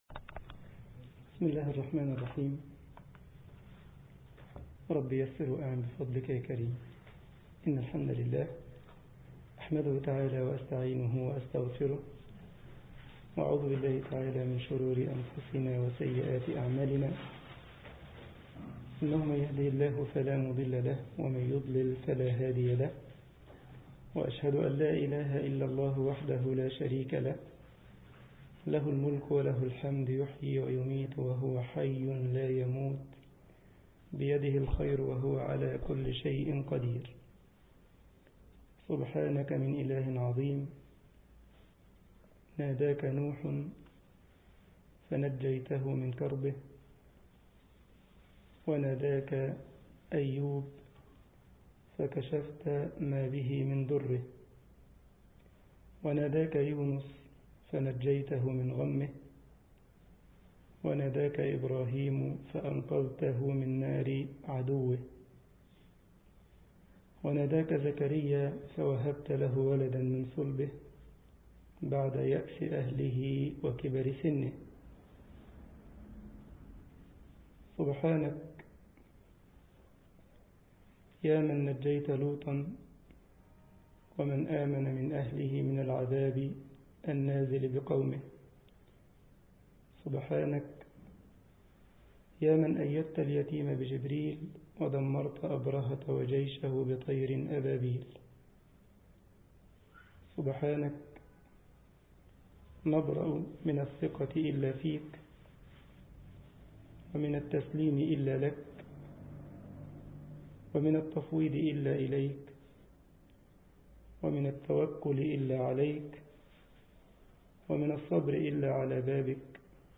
مسجد الجمعية الإسلامية بكايزرسلاوترن ـ ألمانيا درس